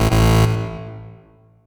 Techmino/media/effect/chiptune/finesseError_long.ogg at 940ac3736cdbdb048b2ede669c2e18e5e6ddf77f
finesseError_long.ogg